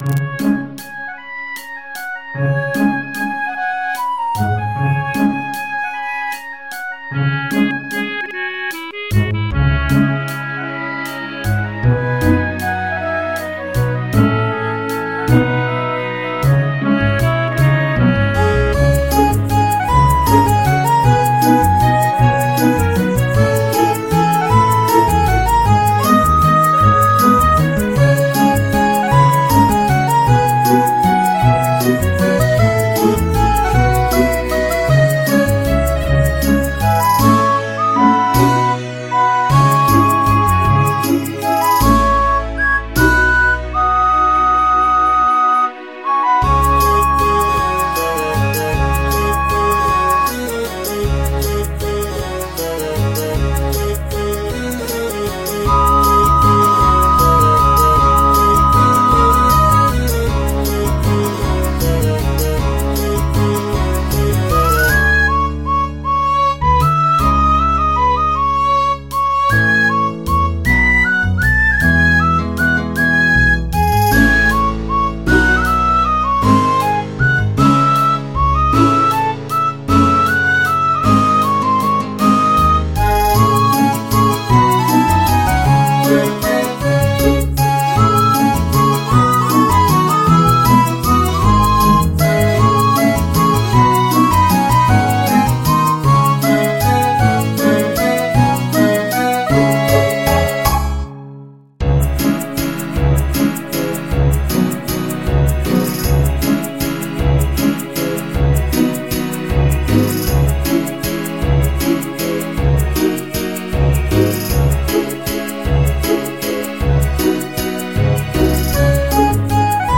MIDI 41.39 KB MP3 (Converted) 3.12 MB MIDI-XML Sheet Music